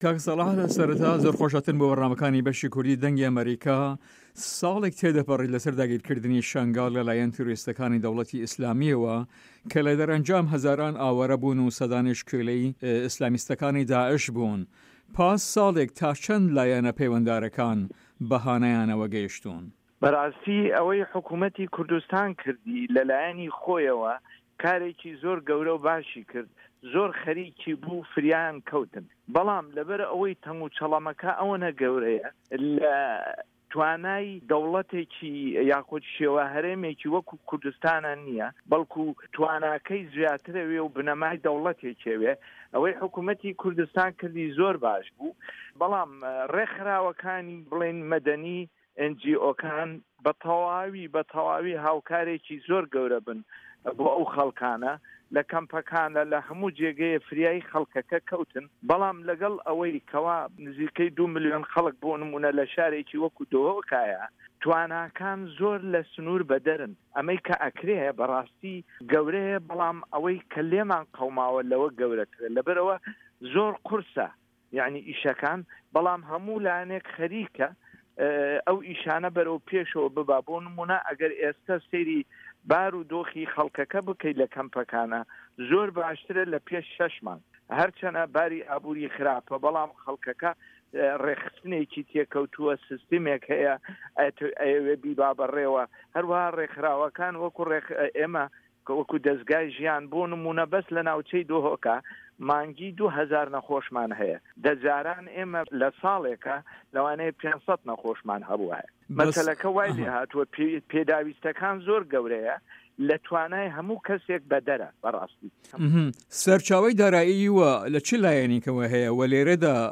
له‌ هه‌ڤپه‌یڤینێکدا